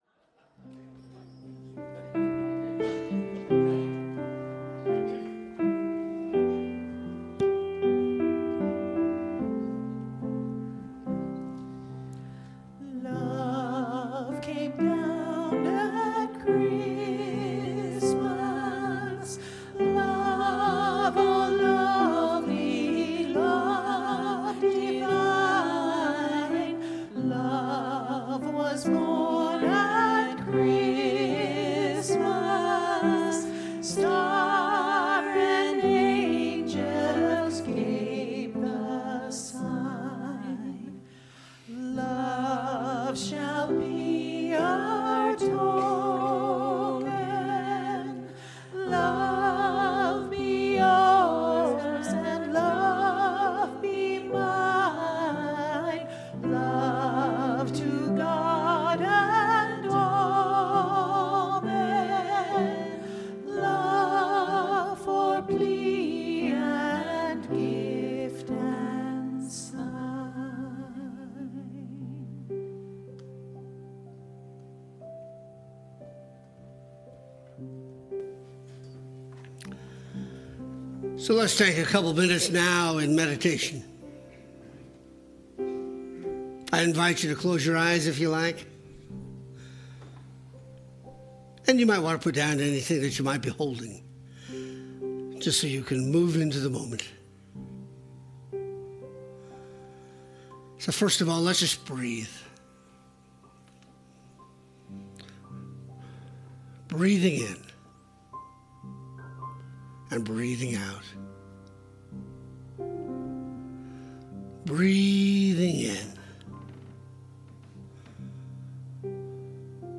The audio recording (below the video clip) is an abbreviation of the service. It includes the Meditation, Lesson and Featured Song.